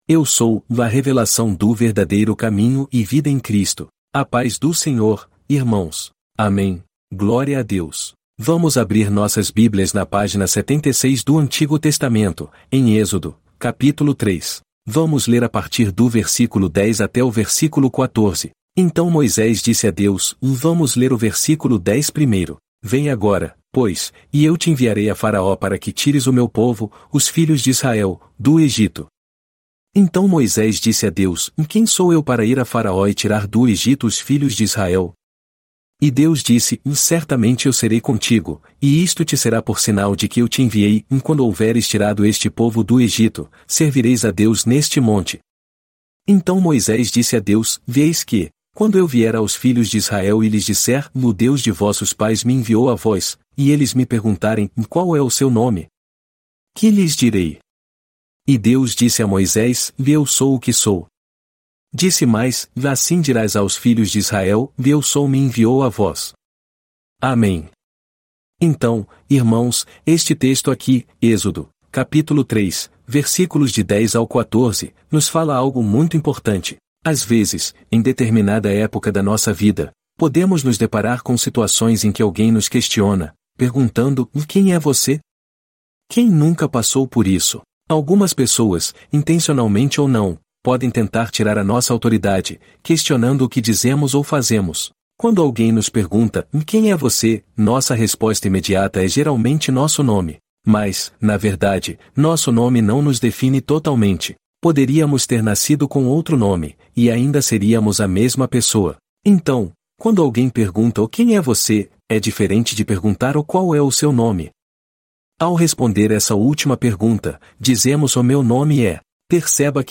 Culto de Adoração